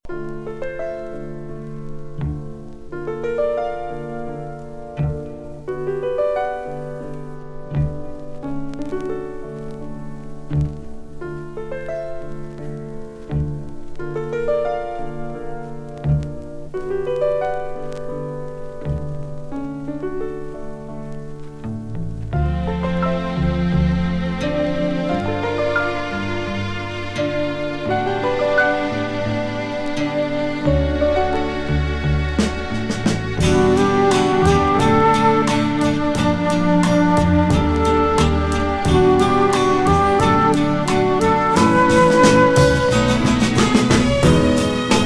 めくるめく美メロ連発の演奏に誘われ、良質さに打たれます。
Pop　USA　12inchレコード　33rpm　Stereo